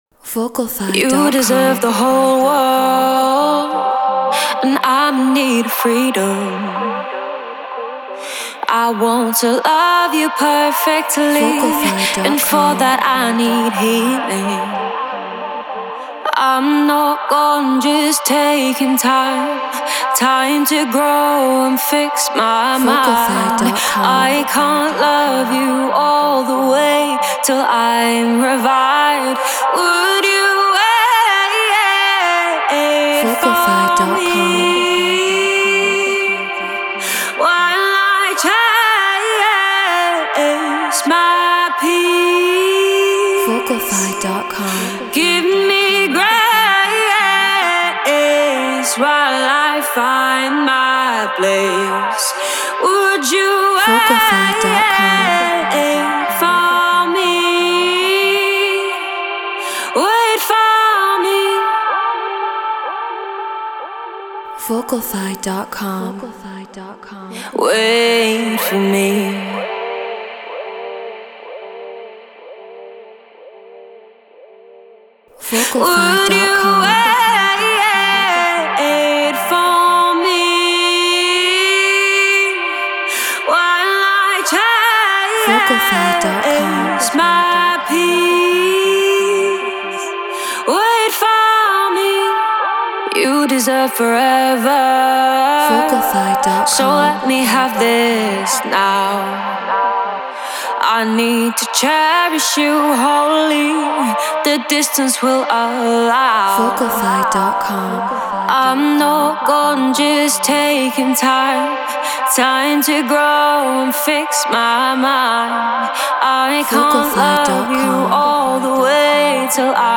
Melodic Techno 125 BPM Gmin
Shure SM7B Focusrite Scarlett Logic Pro Treated Room